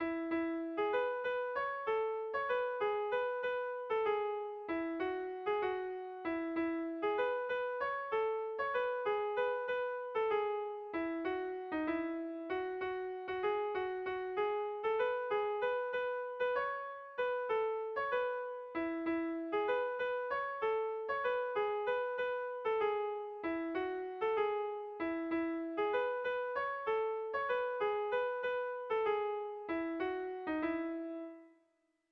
Kontakizunezkoa
Hamarreko handia (hg) / Bost puntuko handia (ip)
A1A2BA3A2